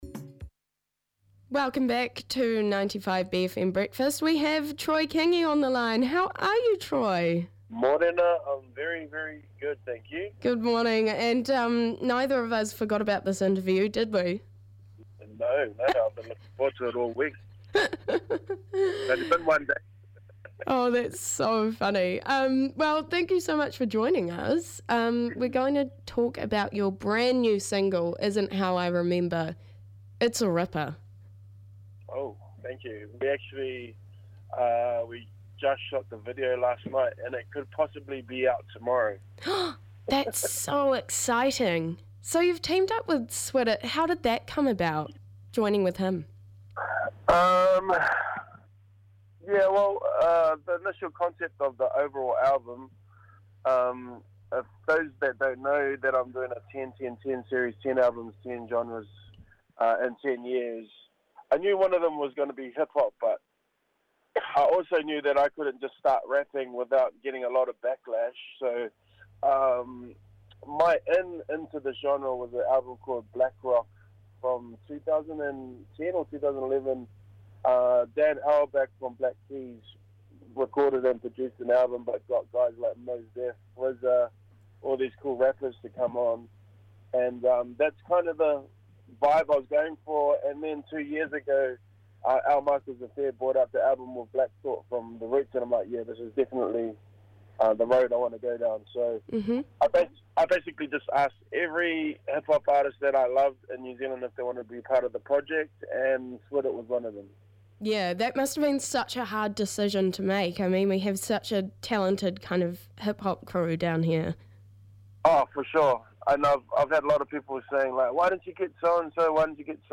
Guest Interview w/ Troy Kingi : Rātu September 30, 2025